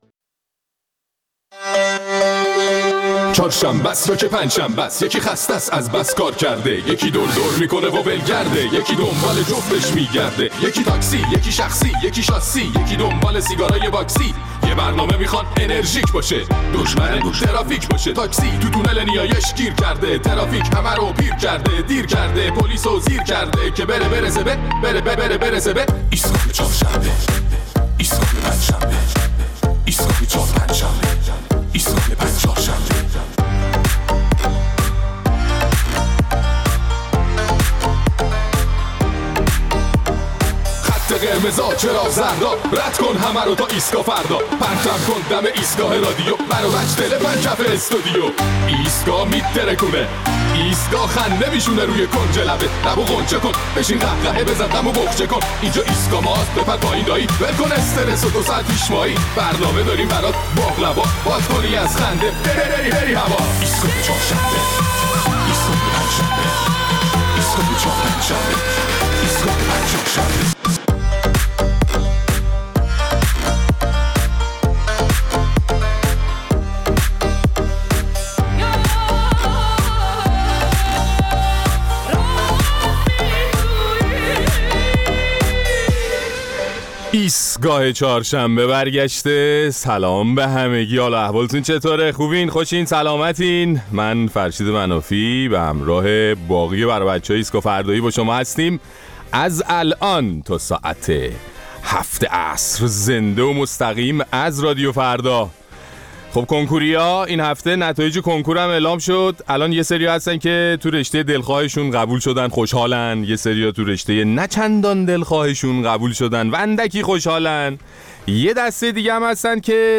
در این برنامه نظر مخاطبین ایستگاه فردا را درباره هجوم بعضی از هموطنان به فروشگا‌ه‌ها برای خریدن اقلام مختلف پرسیده‌ایم و خواسته‌ایم مشاهدات احتمالی خودتان را هم برایمان تعریف کنید.